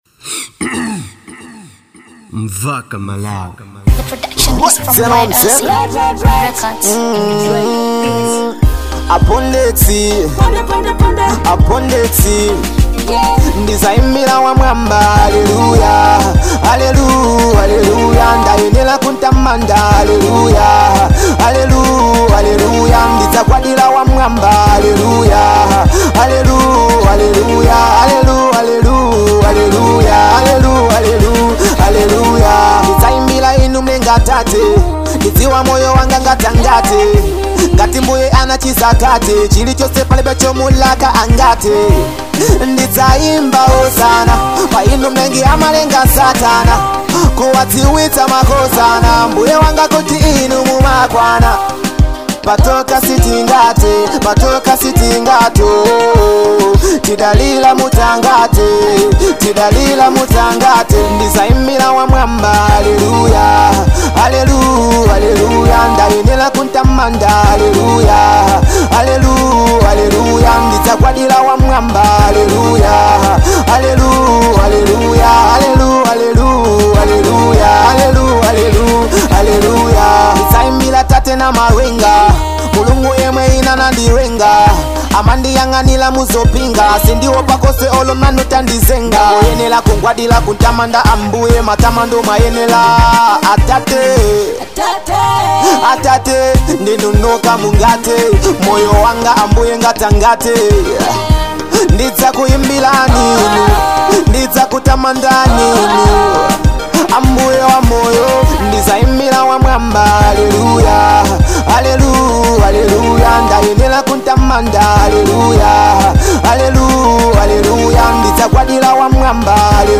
type: Dancehall